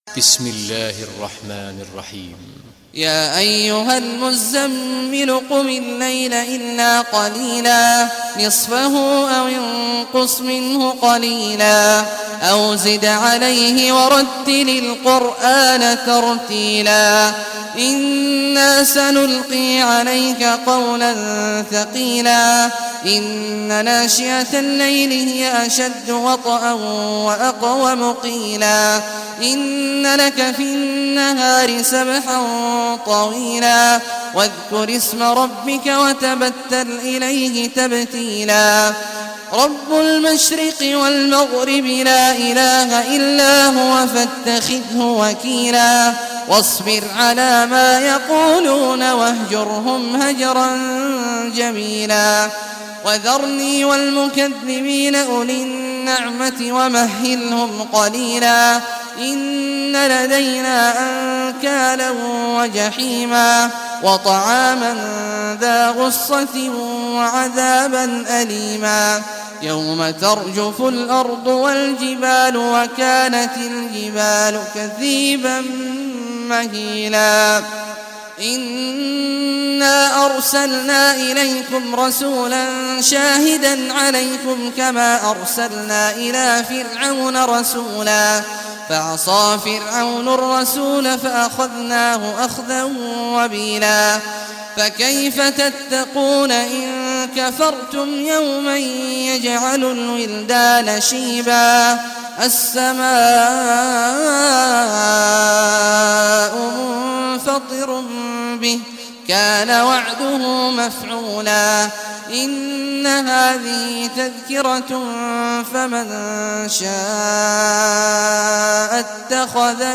Surah Muzammil Recitation by Abdullah Juhany
Surah Muzammil, listen online mp3 tilawat / recitation in Arabic in the beautiful voice of Imam Sheikh Abdullah Awad al Juhany.